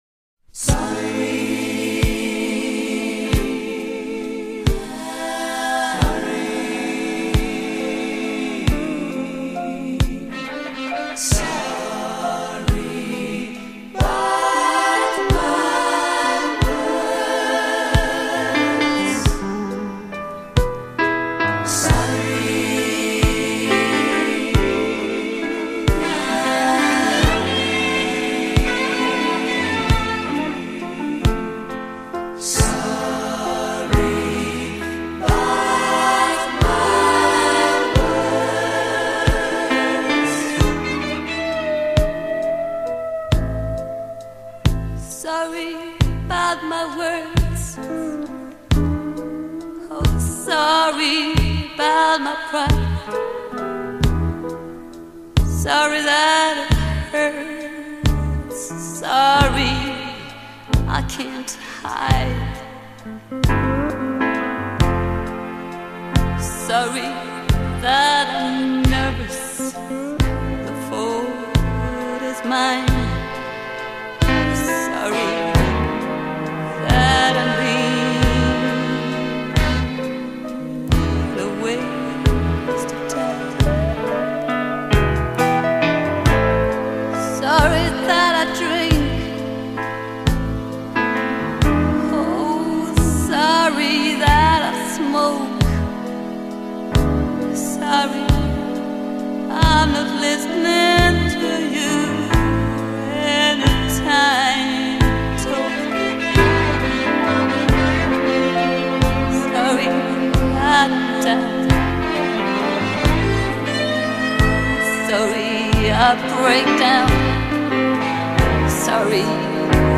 a ballad that was made for the broken hearted ladies